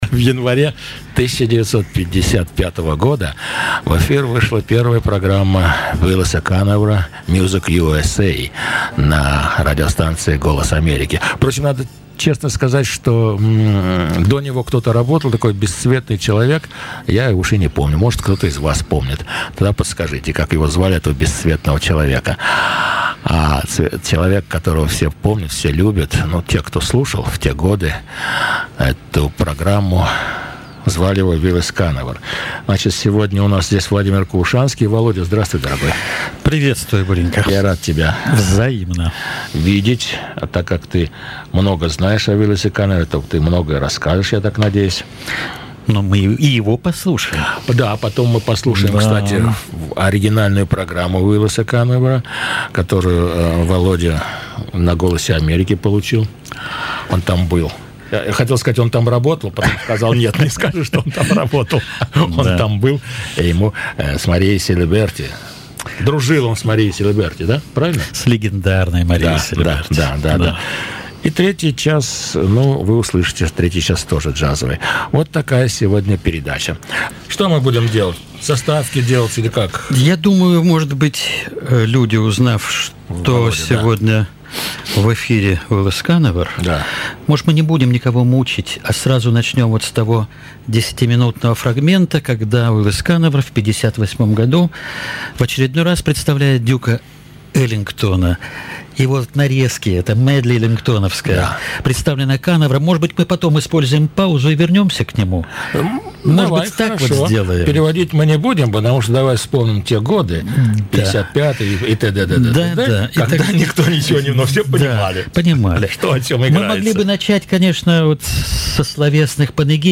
Все,кто слушал музыкальные программы V.O.A. в те годы,никогда не забудут этот бархатный,роскошный голос ведущего. Говорил он очень медленно и очень внятно, объясняя это тем, что передачи идут на коротких волнах,недостаток которых эффект периодического затухания сигнала,кроме того, боль-шинство слушателей слабо или совсем не знали английского языка.